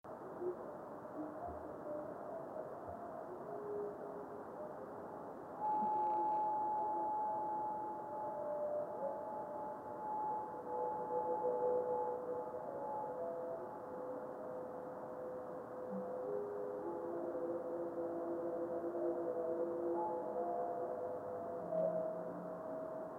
Inconclusive trailing reflection might have been caused by a different meteor out of visual range.